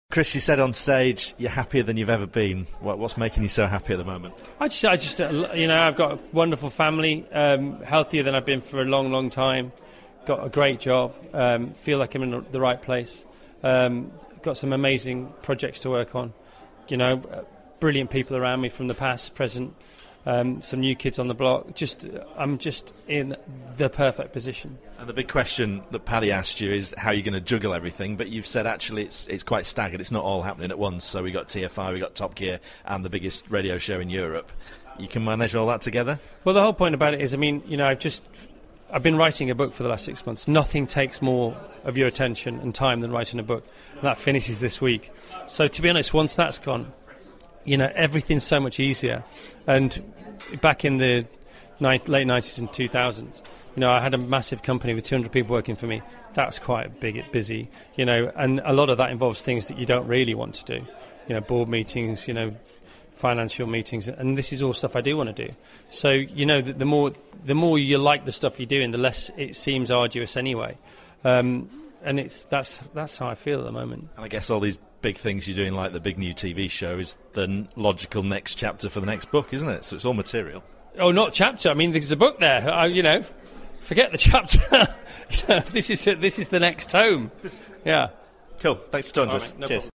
RadioToday Live Interviews / Chris Evans on juggling all his new jobs